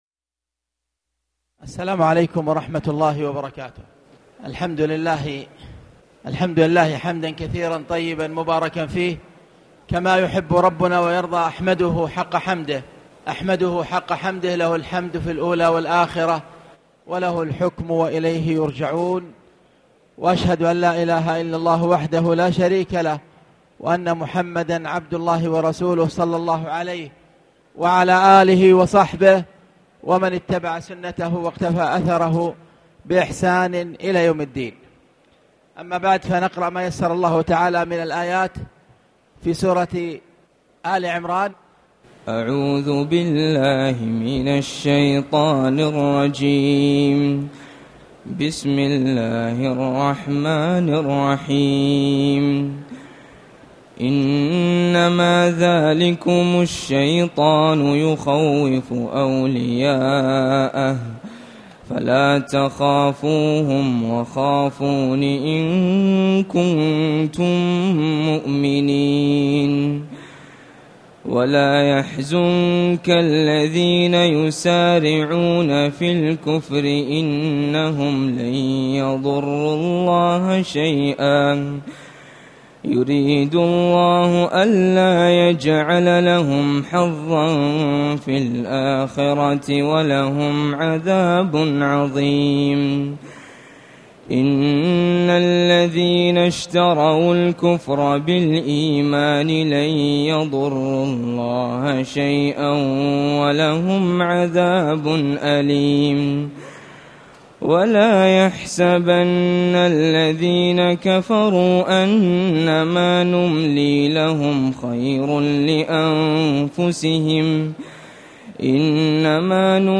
تاريخ النشر ٢٠ رمضان ١٤٣٨ هـ المكان: المسجد الحرام الشيخ: فضيلة الشيخ أ.د. خالد بن عبدالله المصلح فضيلة الشيخ أ.د. خالد بن عبدالله المصلح سورة آل عمران